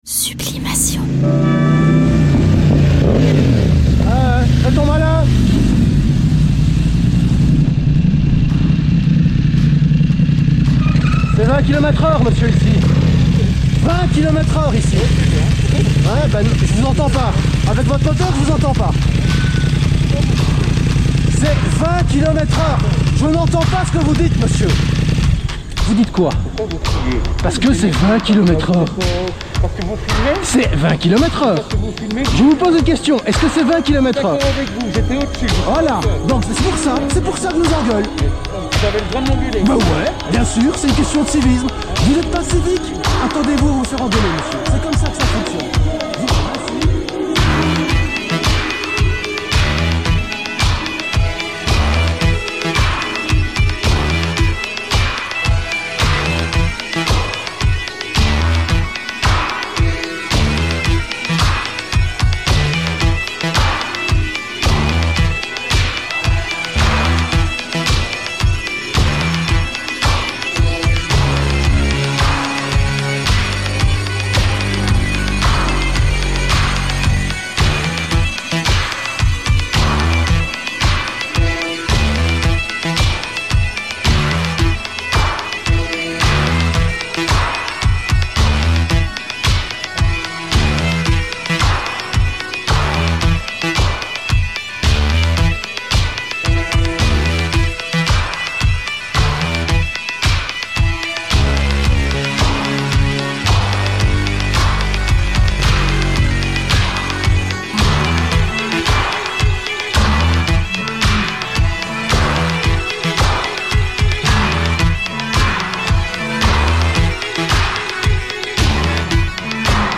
nouveau mix créatif audacieux